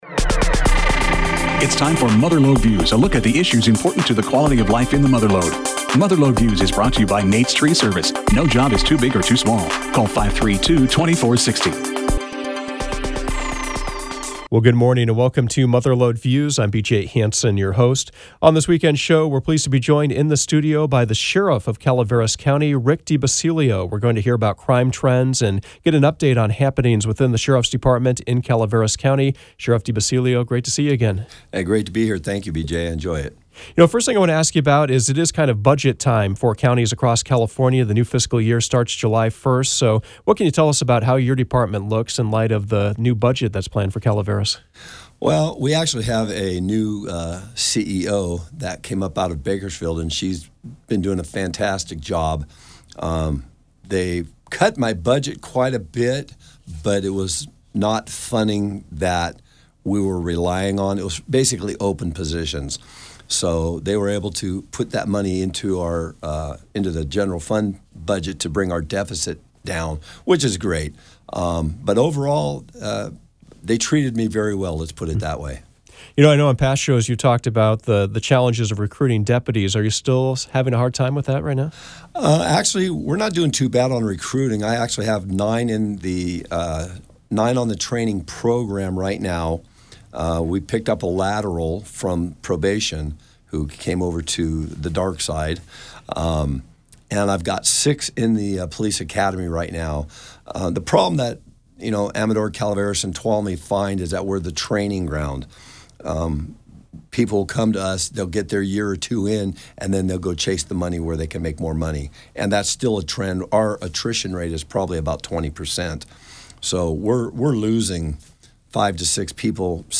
Mother Lode Views featured Calaveras County Sheriff, Rick DiBasilio. He talked about recent state decisions that he feels are leading to more criminal activity in Calaveras County. He also spoke about his new budget, staffing concerns, and general crime trends.